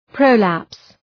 Προφορά
{‘prəʋlæps}